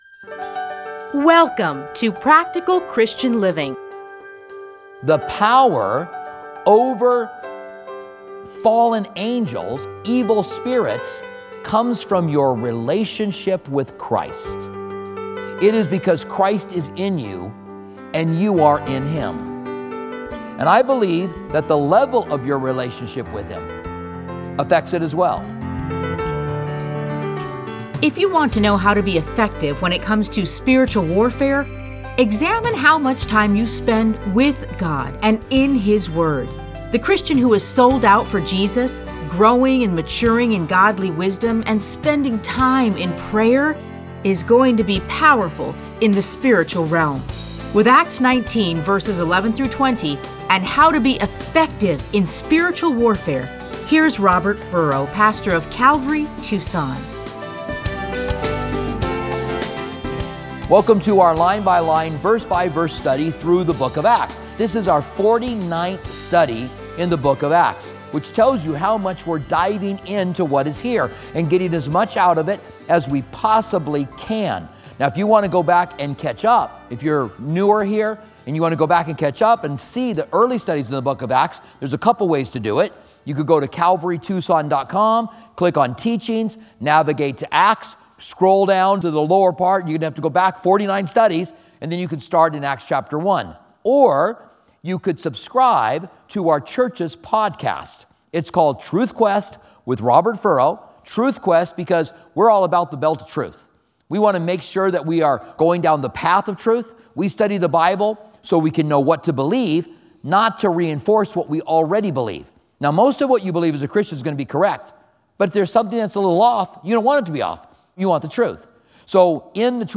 Listen to a teaching from Acts 19:11-20.